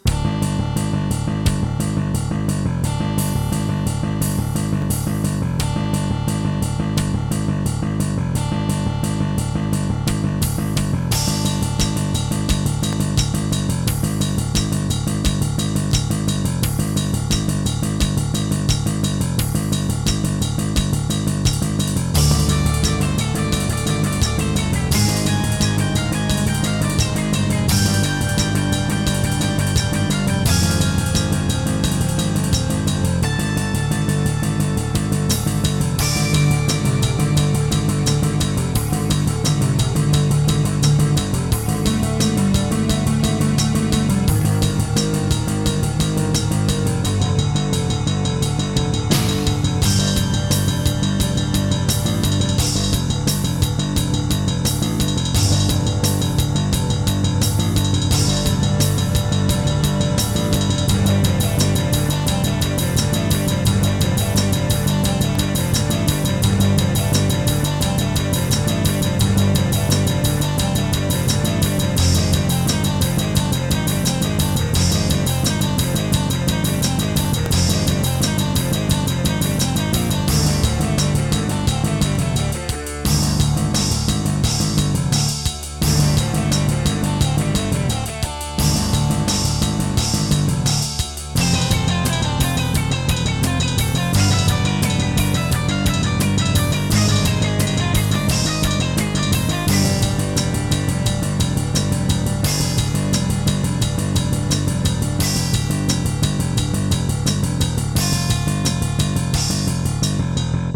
Music: GM midi
Creative WaveBlaster ct1910